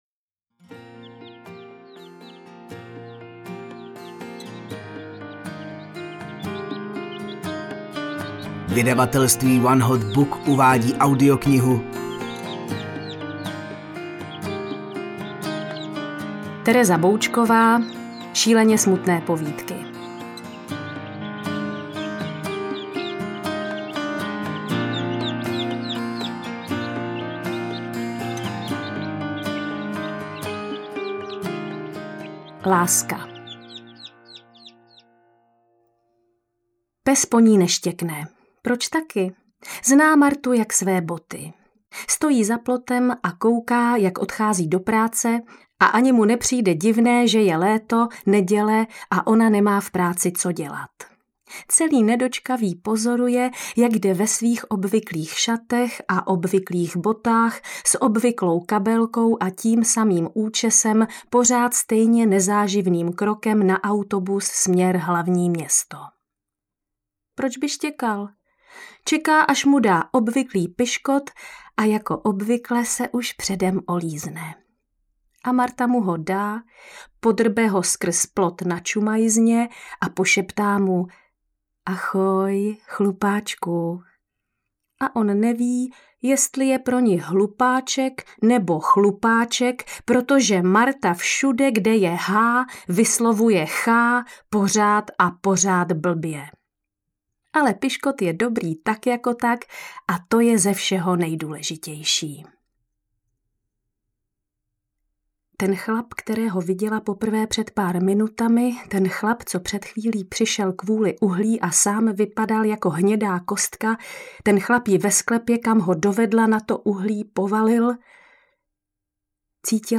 Interpret:  Tereza Boučková
Knihu jsem "cetla" ve forme audioknihy, kterou namluvila samotna autorka.
AudioKniha ke stažení, 13 x mp3, délka 4 hod. 16 min., velikost 353,0 MB, česky